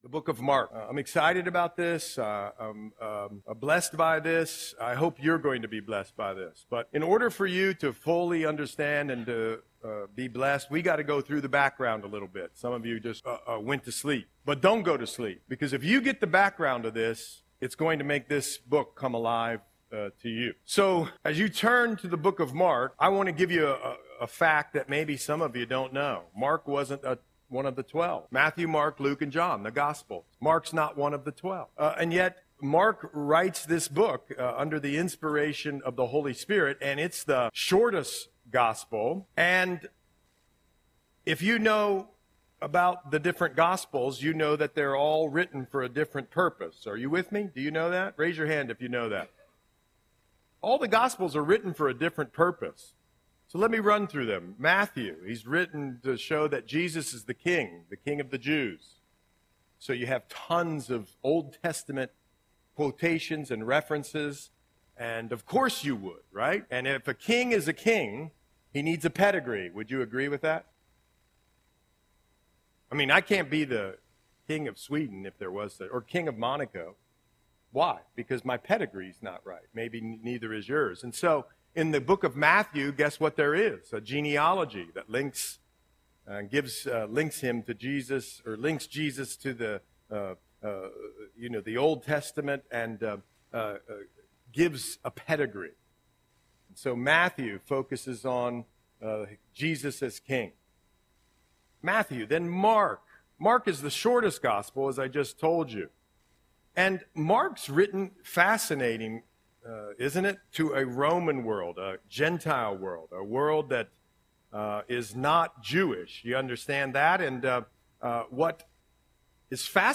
Audio Sermon - October 6, 2024